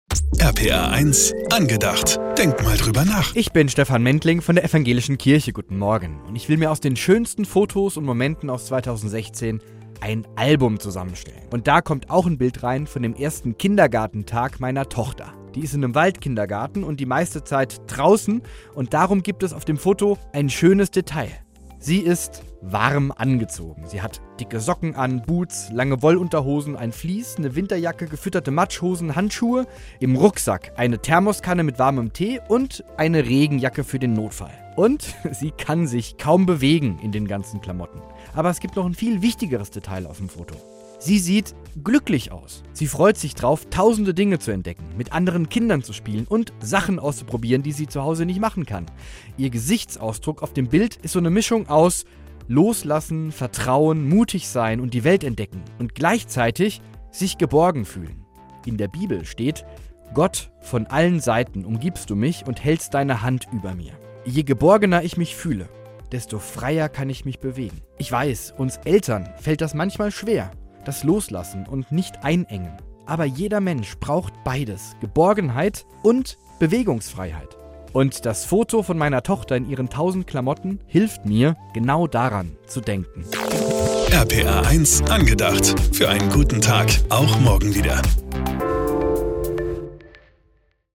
ProtCast Pfalz - Radioandachten aus Rheinland-Pfalz